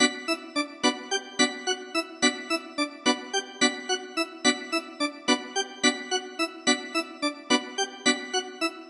描述：欢快的风琴。